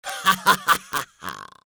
Sinister Laughs Male 02
Sinister Laughs Male 02.wav